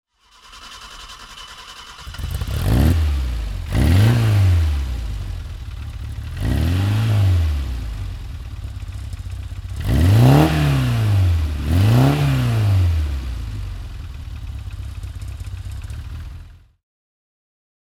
Fiat-Abarth 850 TC Nürburgring (1963) - Starten